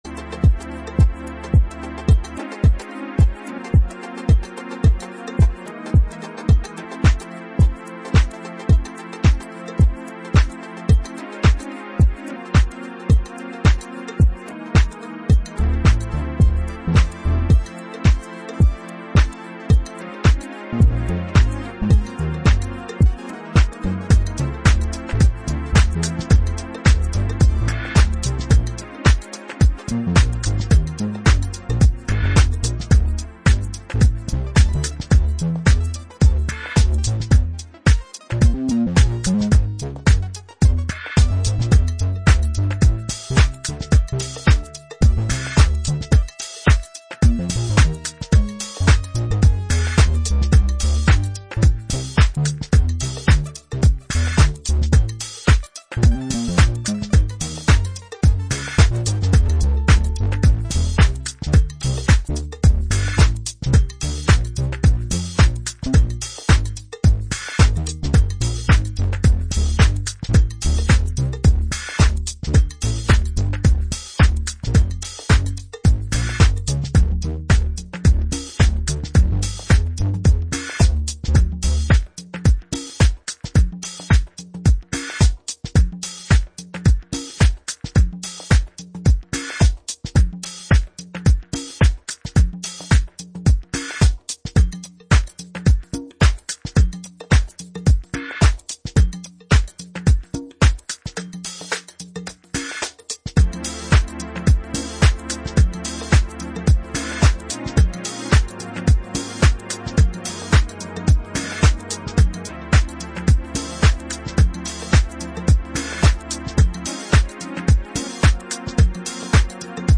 House Jazz Soul